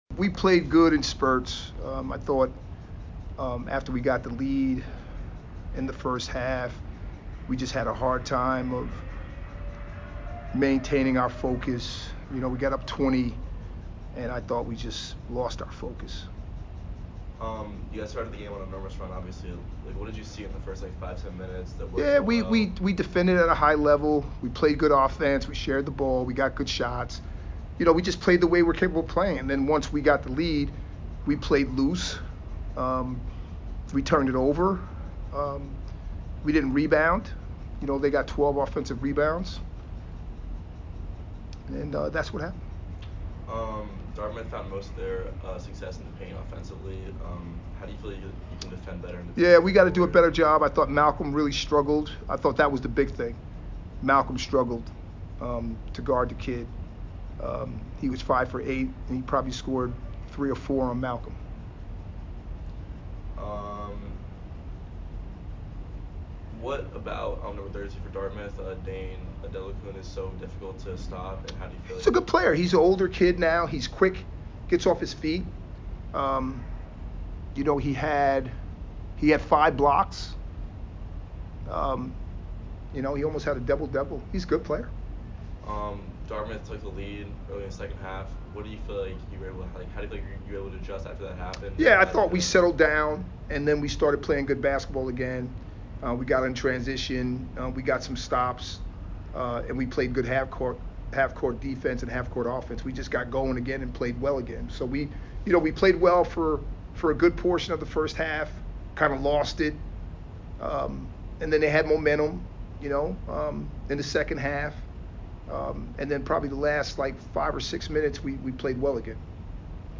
Men's Basketball / Dartmouth Postgame Interview (12-13-22)